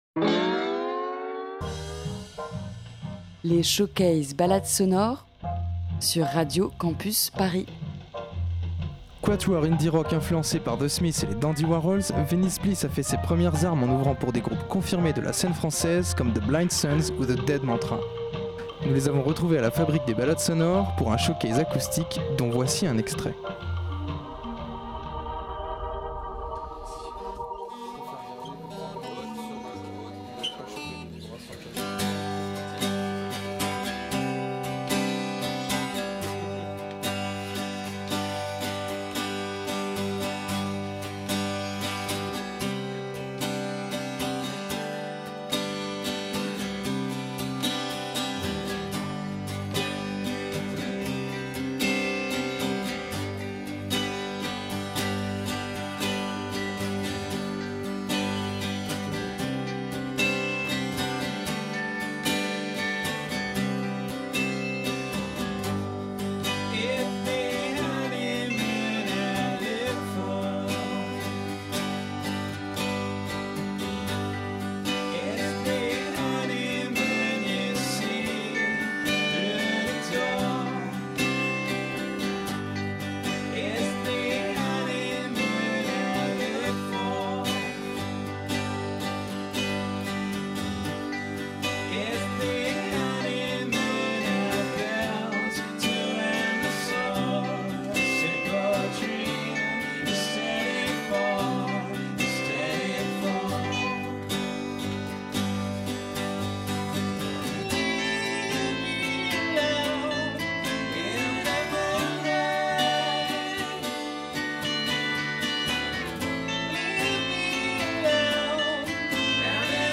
aux sonorités garage et psyché